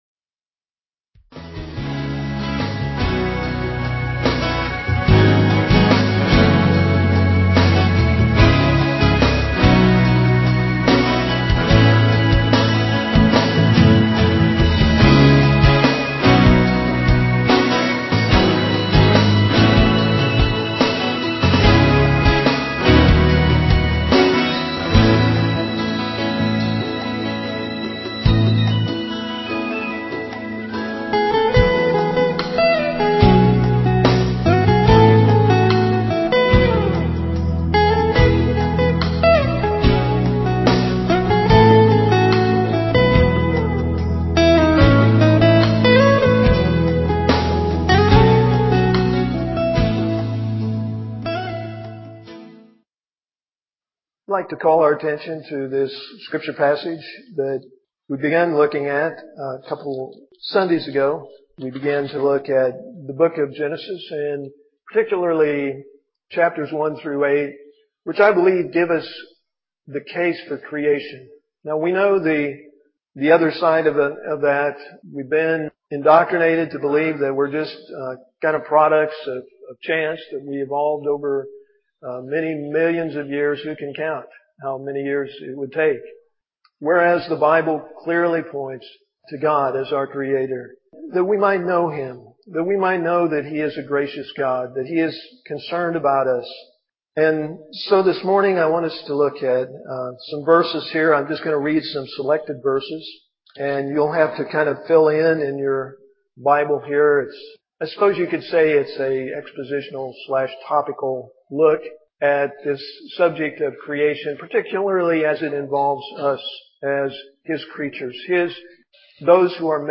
PLAY: Case for Creation, Part 3, January 16, 2011 Scripture: Genesis, chapters 1-3 (excerpts). Message given